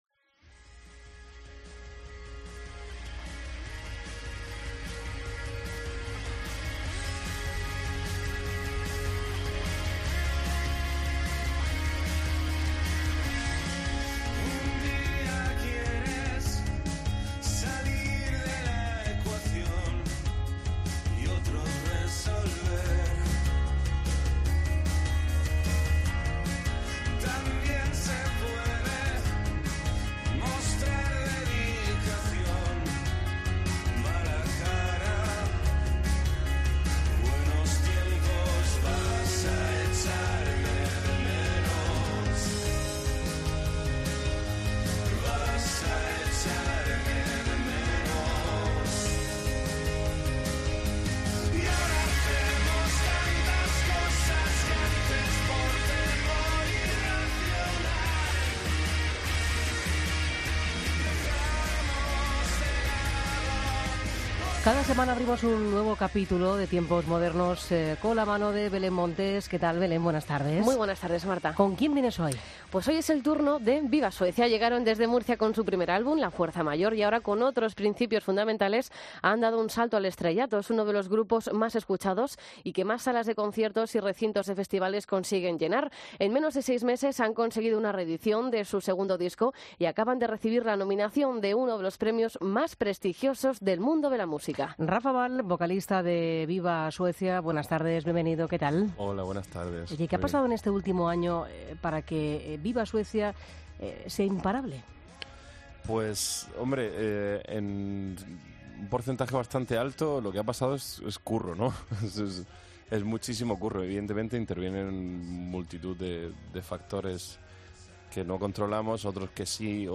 La luz de La Linterna Entrevista